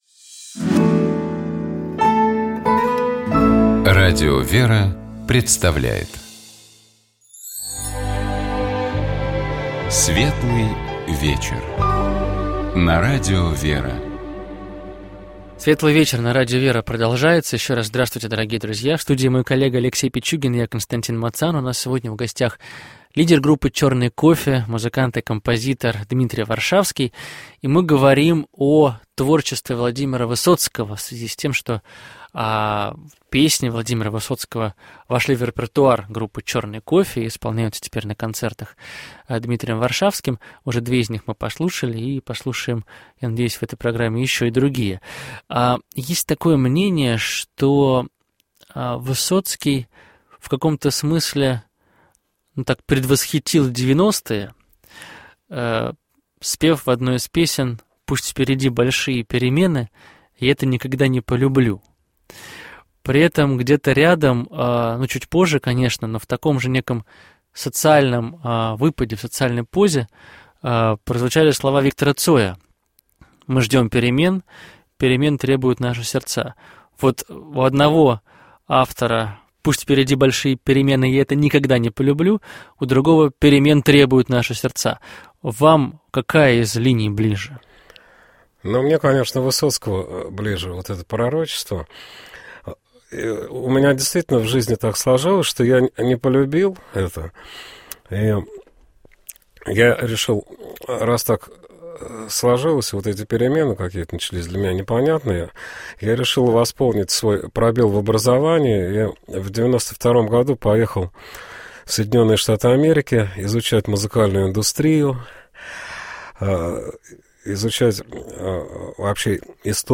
У нас в гостях был известный музыкант, певец, лидер группы «Черный кофе» Дмитрий Варшавский.